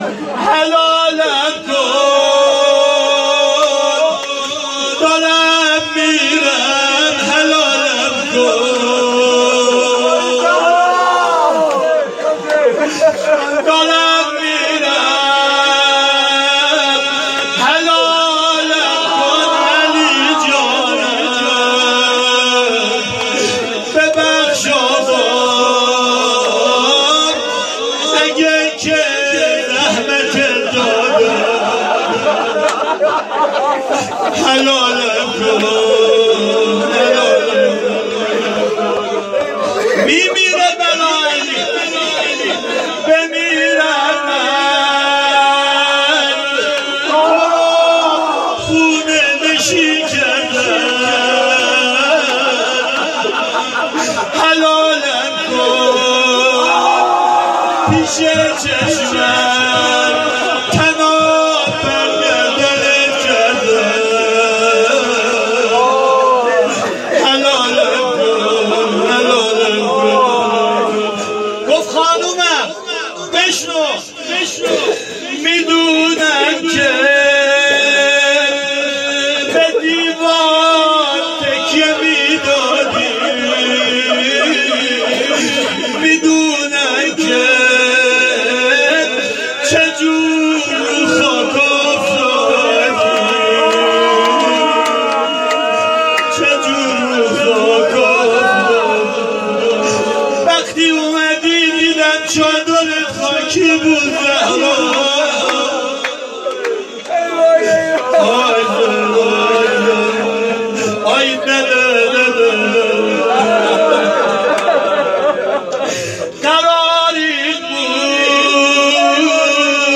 آرشیو ایام فاطمیه